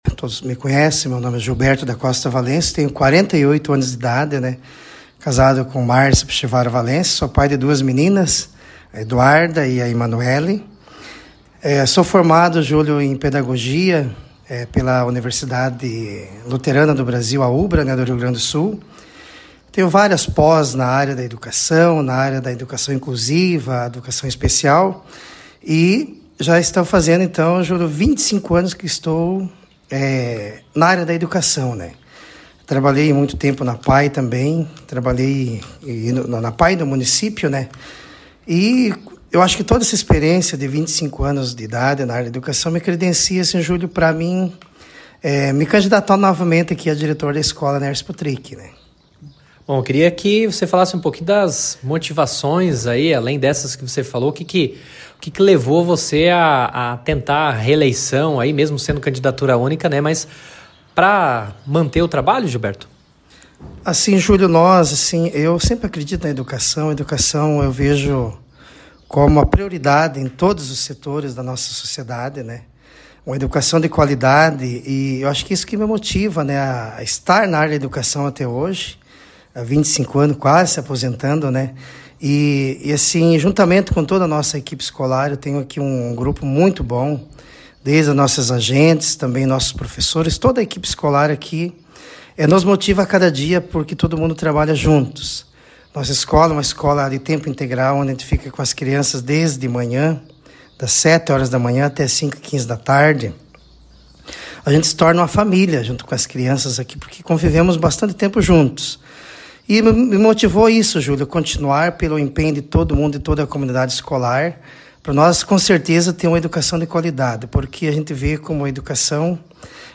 conversou com a nossa reportagem sobre sua candidatura e projetos para a instituição: